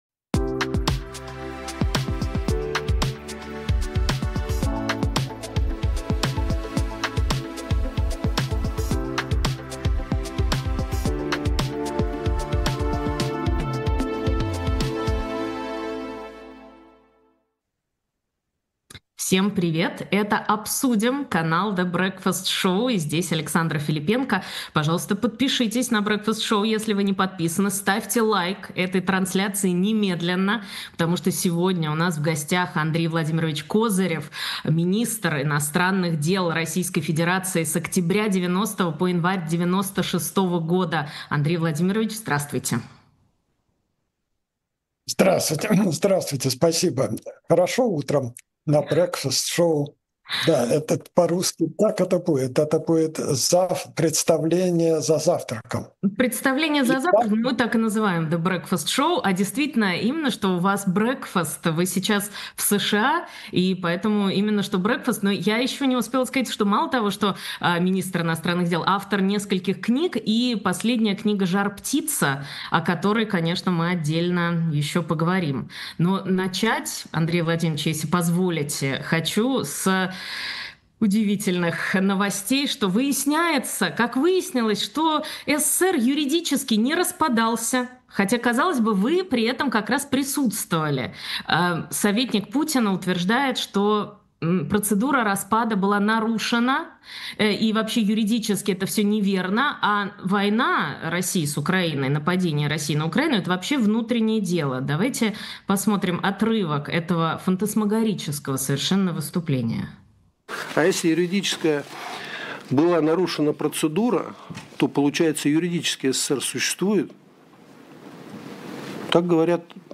кандидат исторических наук Андрей Козырев министр иностранных дел РФ в 1990-1996 гг.